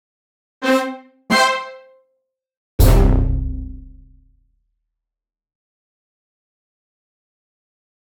Here are some audio files (44.1kHz/24Bit)
... well to test how the tail behaves with percussion-like sounds (echoes? or tail?)
cre_tail_test_hits.wav